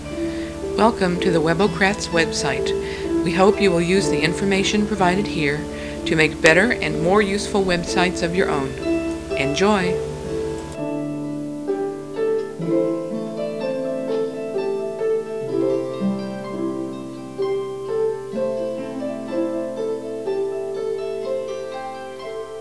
[A long welcoming wave file] Welcome message (.wav, 500k)